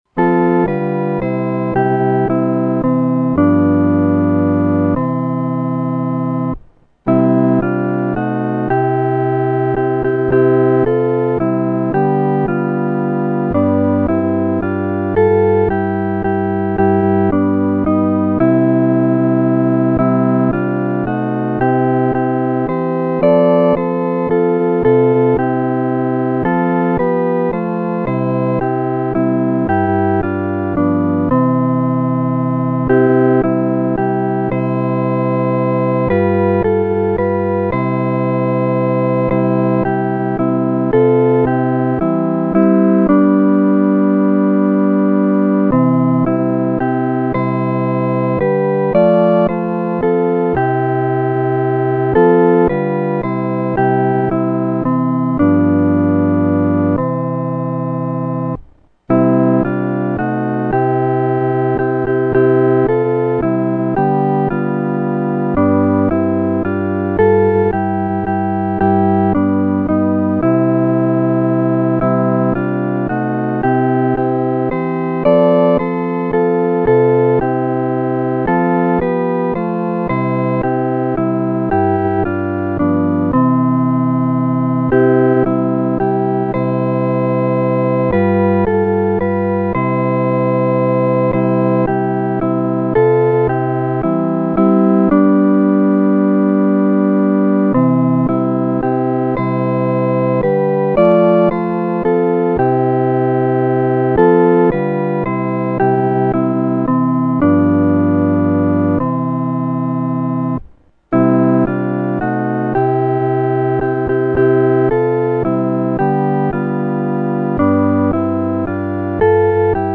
合奏（四部声）
主翅膀下-合奏（四声部）.mp3